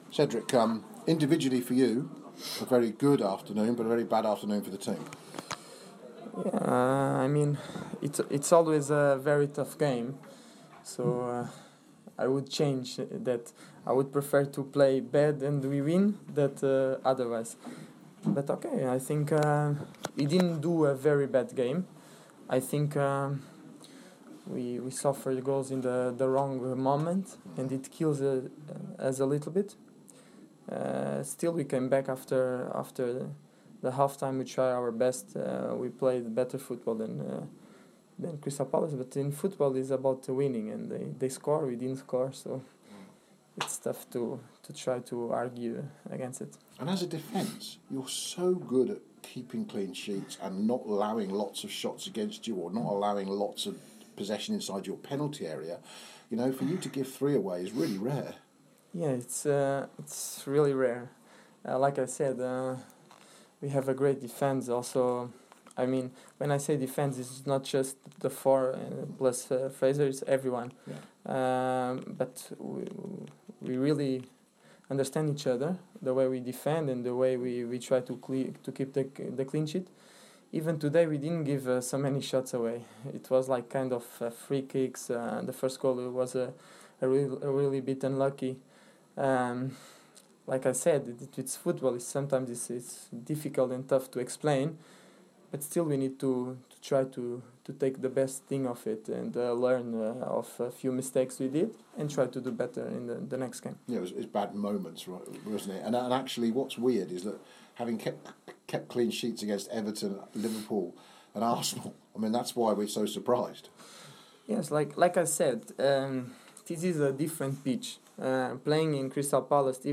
REACTION